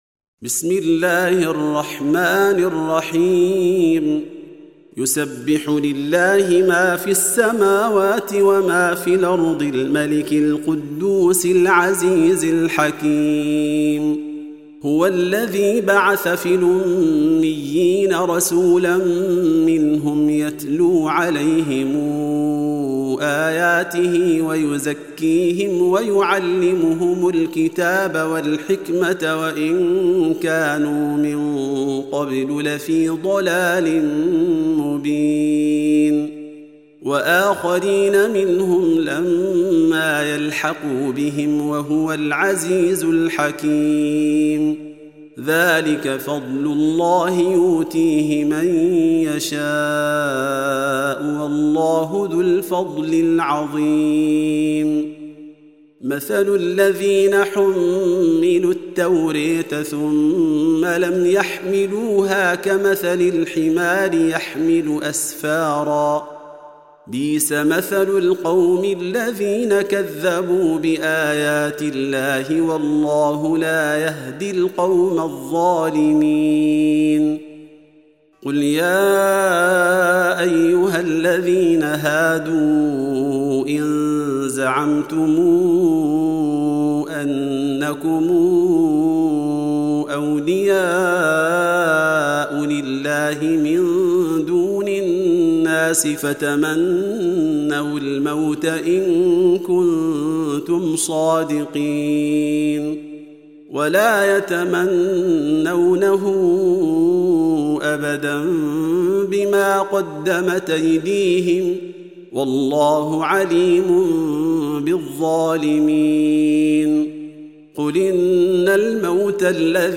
Surah Repeating تكرار السورة Download Surah حمّل السورة Reciting Murattalah Audio for 62. Surah Al-Jumu'ah سورة الجمعة N.B *Surah Includes Al-Basmalah Reciters Sequents تتابع التلاوات Reciters Repeats تكرار التلاوات